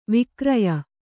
♪ vikraya